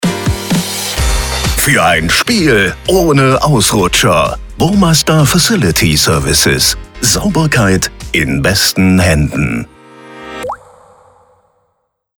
Stadionansagen – Sponsoring:
Sponsoring-Jingle z.B. bei Sportveranstaltungen